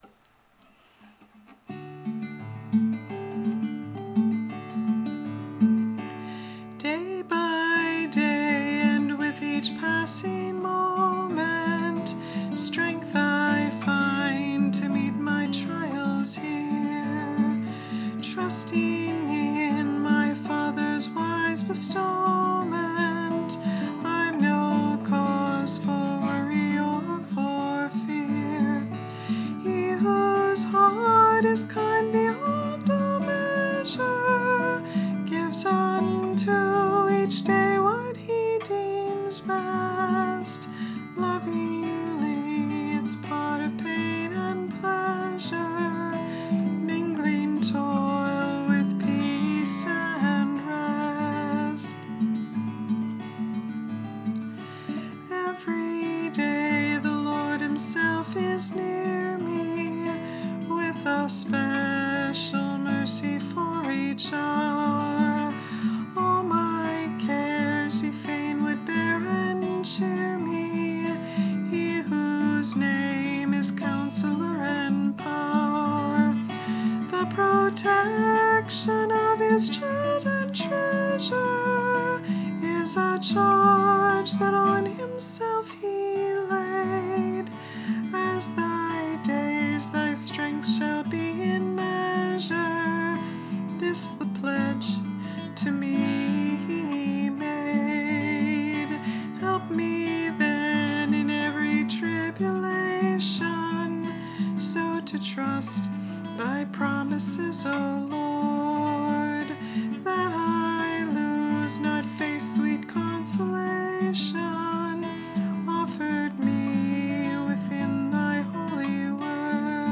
The words will be posted so you can sing along with me.